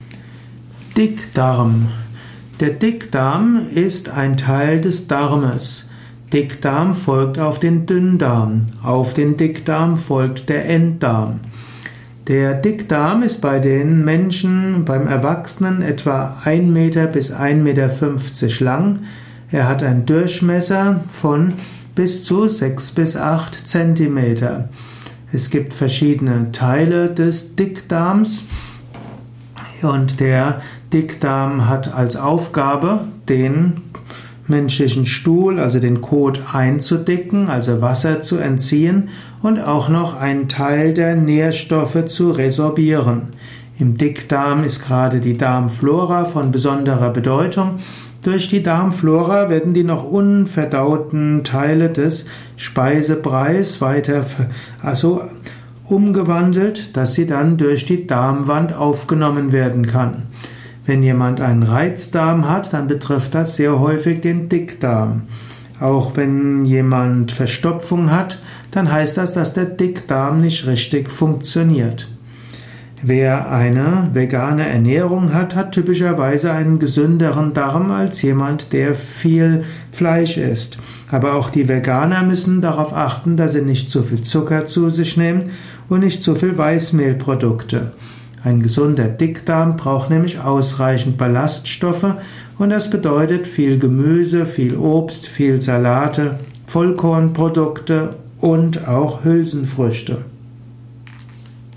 Ein Kurzvortrag über den Dickdarm